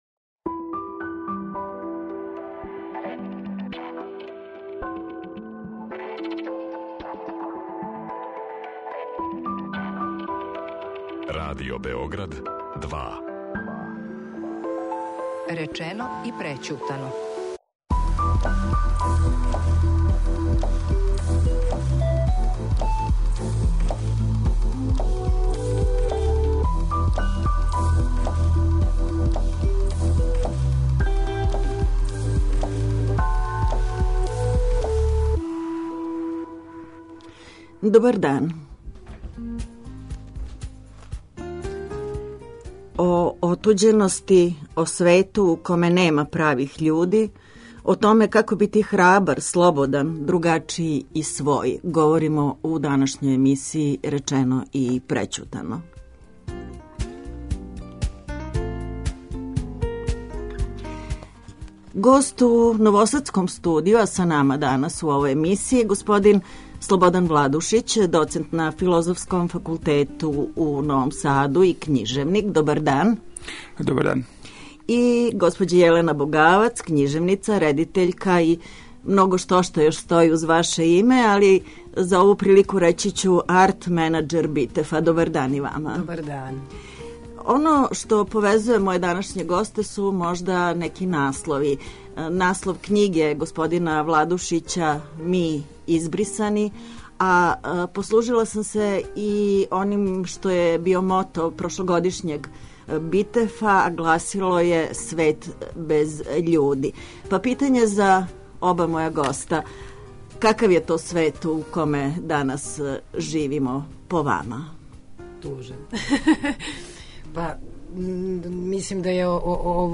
Гост у новосадском студију